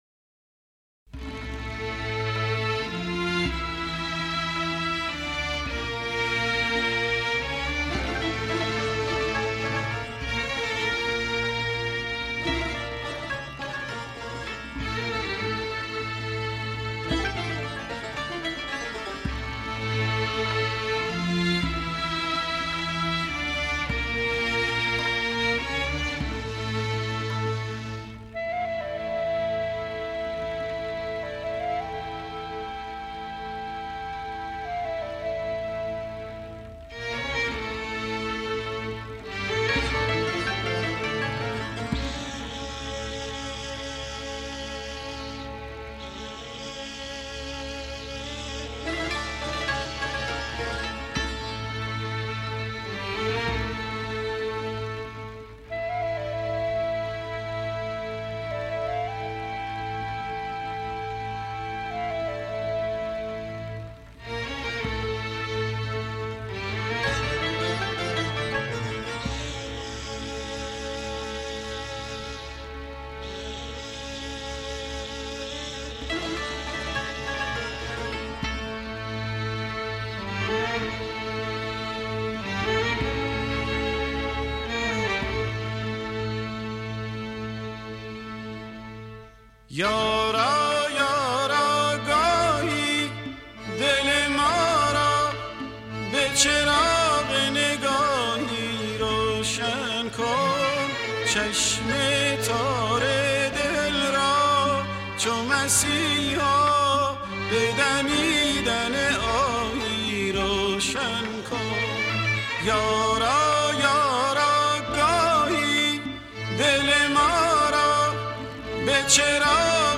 با صدای لطیف و پرحس